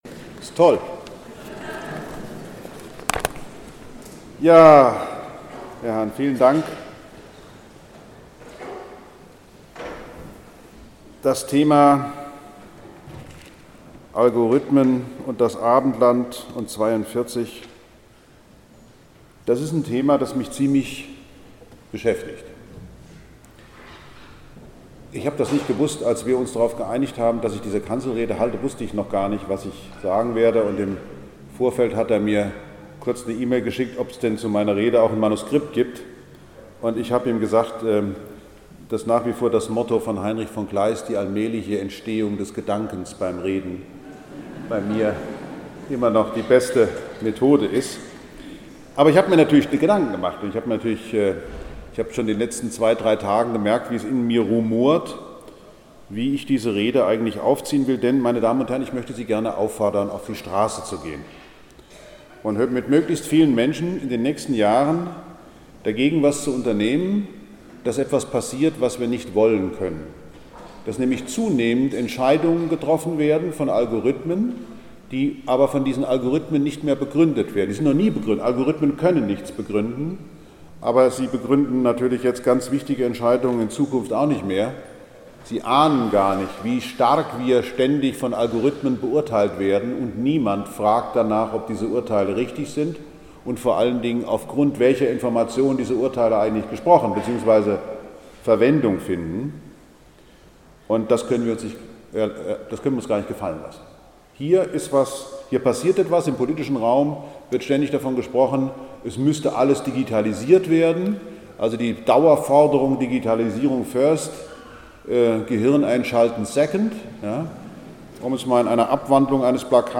Gesellschaftlicher Diskurs über Algorithmen nötig - Astrophysiker Lesch hielt Kanzelrede - Evangelische Akademie Tutzing
Überdies seien Algorithmen nicht in der Lage, die existentiellen Fragen der Menschen zu beantworten, so Lesch in der voll besetzten Erlöserkirche.
Kanzelrede_Lesch_EAT_2018_Teil-1.mp3